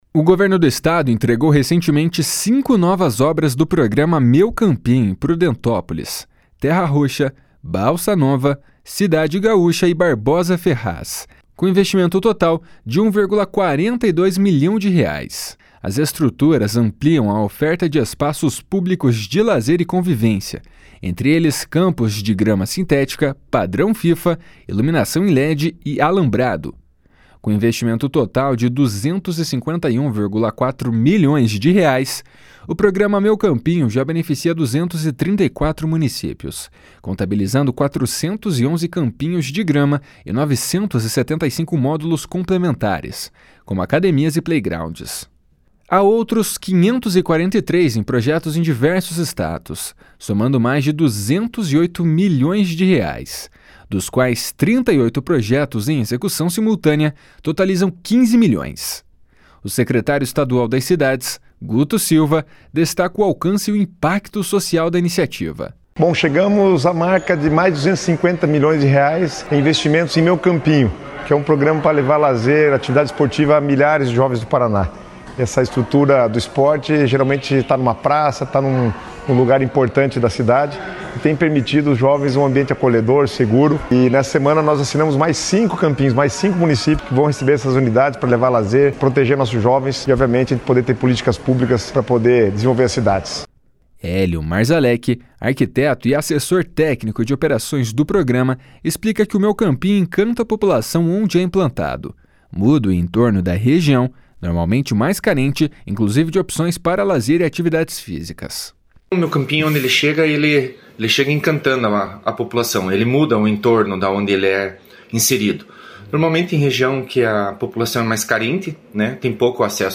O secretário estadual das Cidades, Guto Silva, destacou o alcance e o impacto social da iniciativa. // SONORA GUTO SILVA //